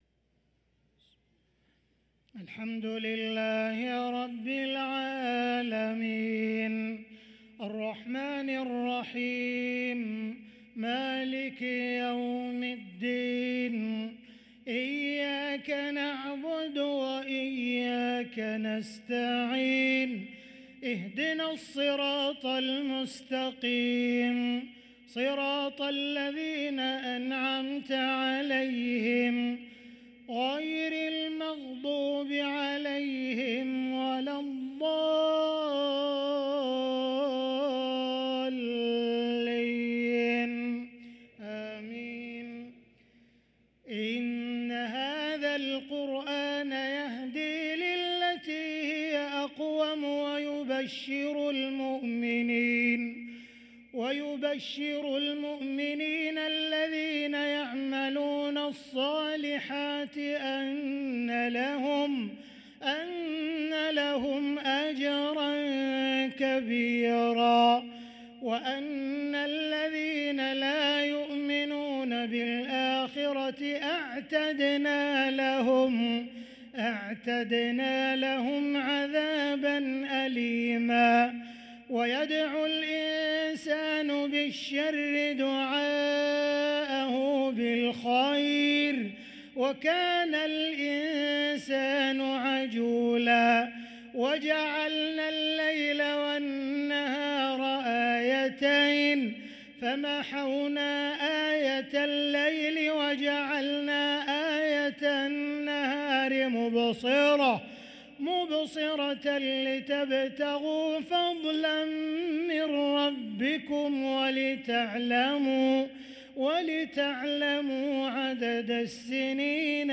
صلاة العشاء للقارئ عبدالرحمن السديس 21 صفر 1445 هـ